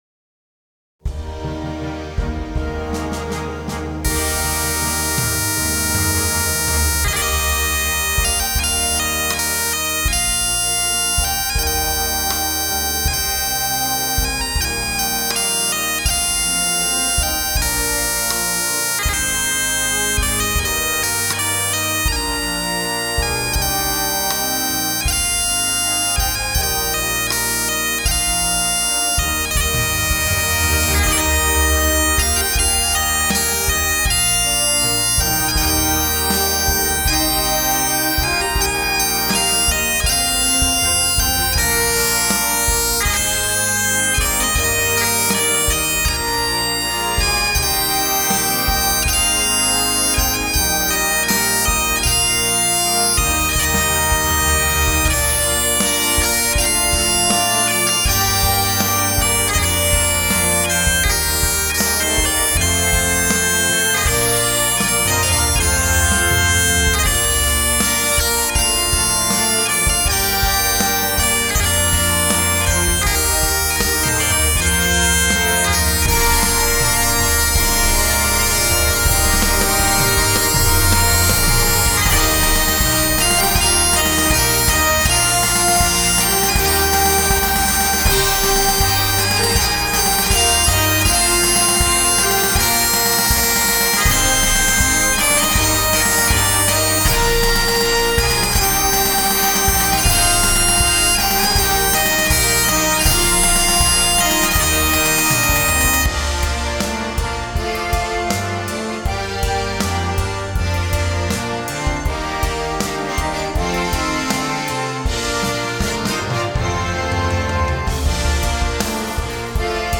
Timpani
Drum Set
Side Drum / Cymbals / Bass Drum